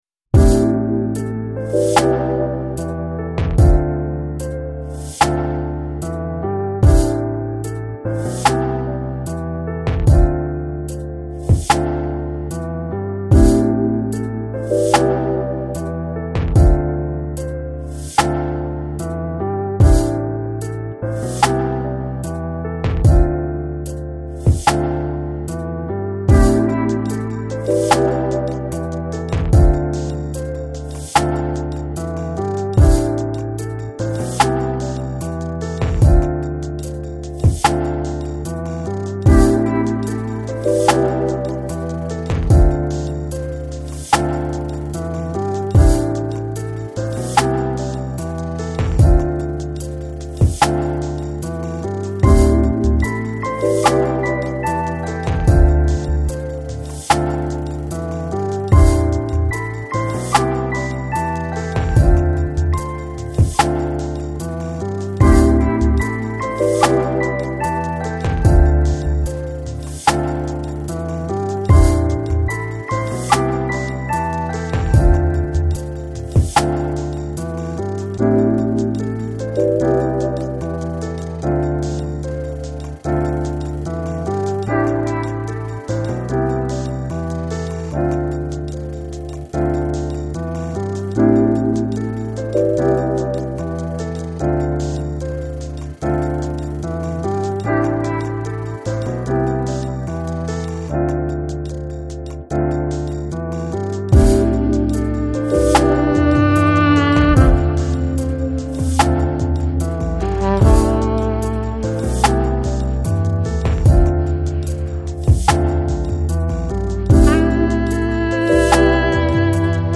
Жанр: Downtempo, Chillout